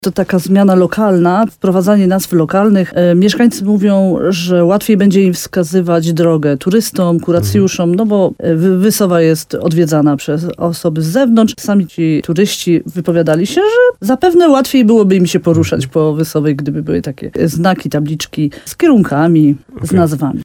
Rozmowa z wójt gminy Uście Gorlickie: Tagi: ulice HOT gmina Uście Gorlickie Wysowa-Zdrój nazwy ulic Ewa Garbowska-Góra